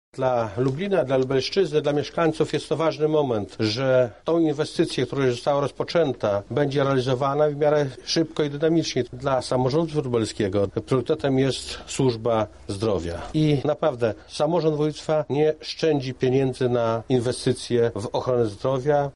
O roli inwestycji mówi Arkadiusz Bratkowski, Członek Zarządu Województwa Lubelskiego.